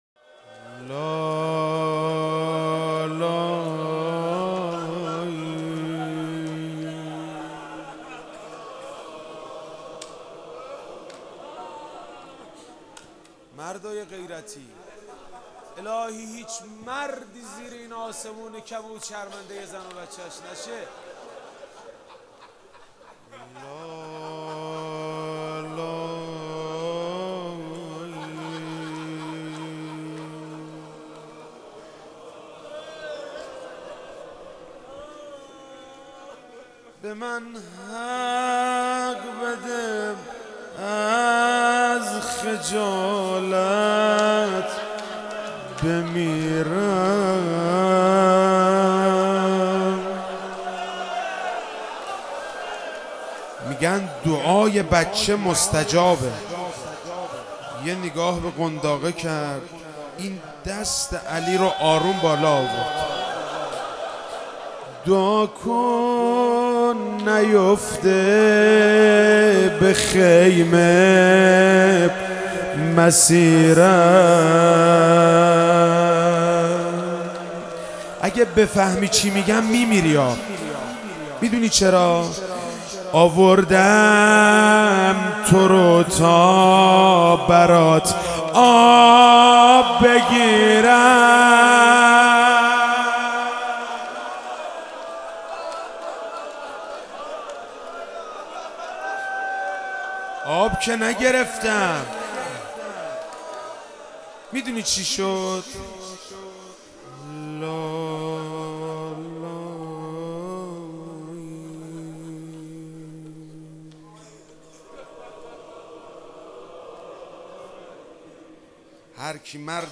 محرم 98 شب هفتم - روضه - لالایی به من حق بده از خجالت بمیرم